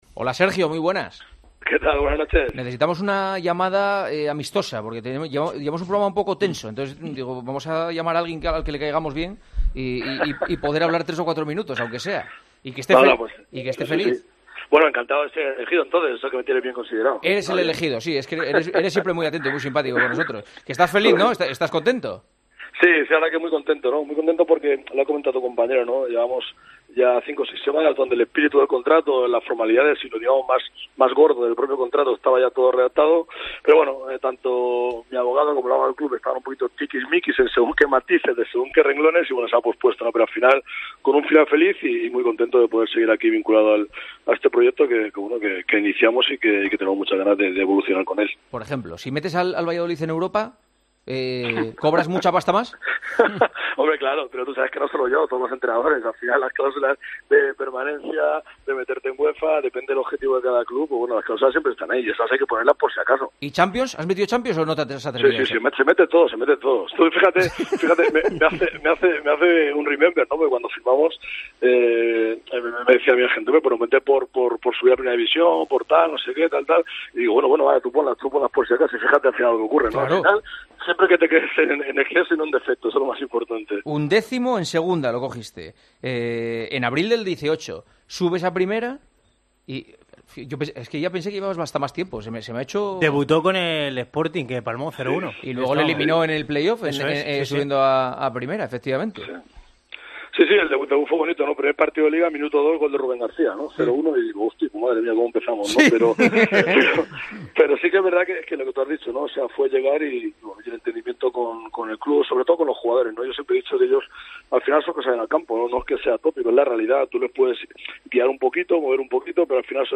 "Si meto al club en Europa gano mucha pasta, sí. Pero no yo, todos los entrenadores", ha bromeado el entrenador del conjunto pucelano durante la entrevista en El Partidazo de COPE de este jueves.